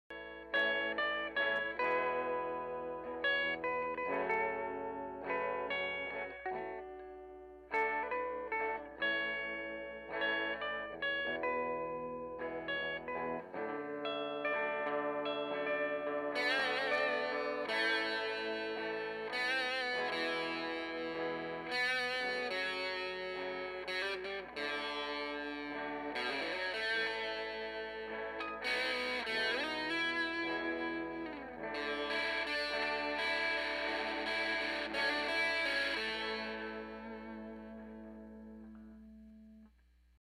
Car Sound Effects Free Download
car sound effects free download